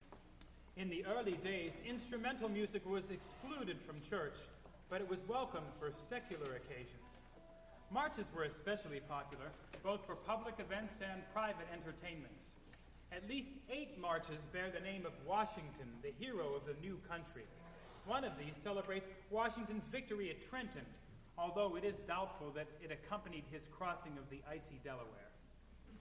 Spoken intro for The Battle of Trenton